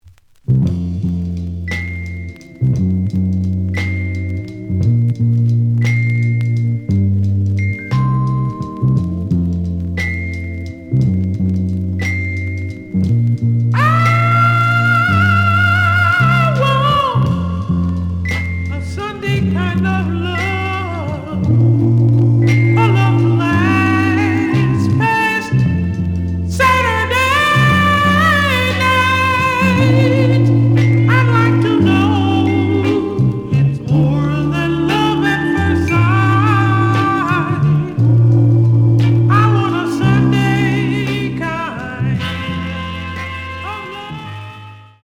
The audio sample is recorded from the actual item.
●Genre: Soul, 60's Soul
Slight damage on both side labels. Plays good.)